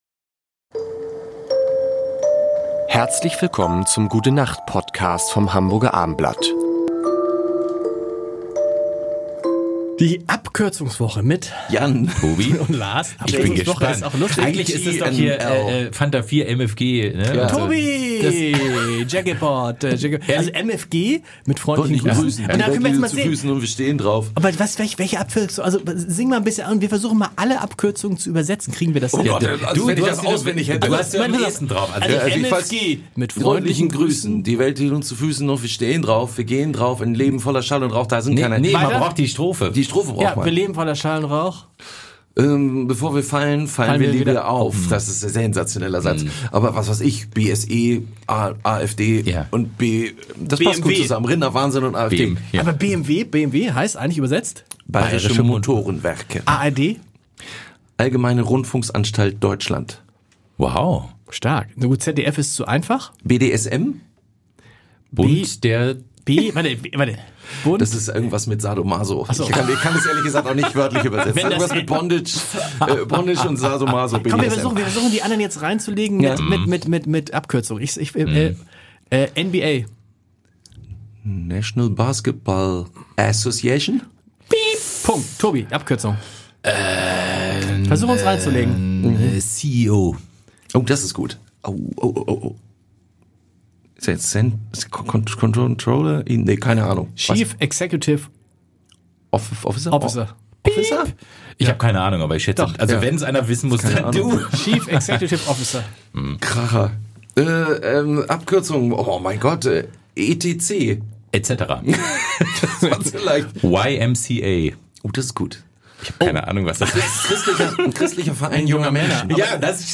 Sie erwarten unterhaltsame, nachdenkliche und natürlich sehr musikalische fünf Minuten.